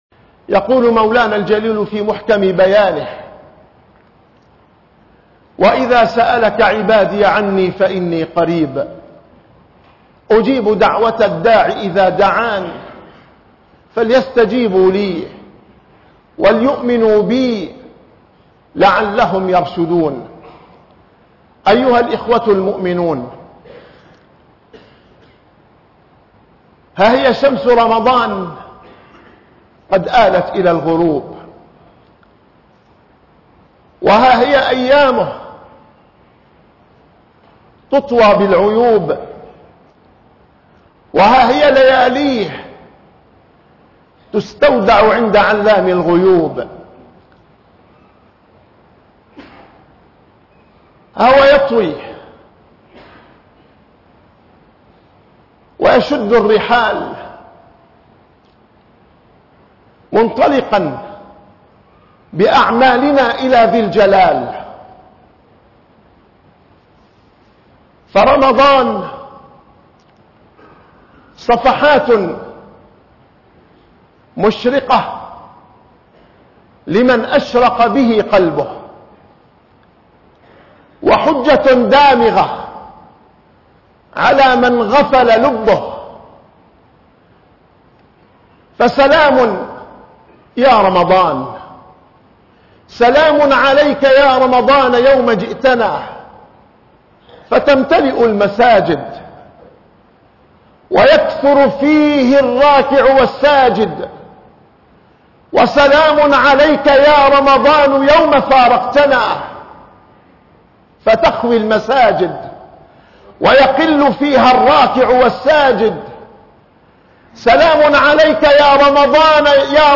- الخطب